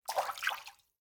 splash-v2.ogg